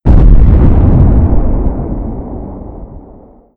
cannon_echo.mp3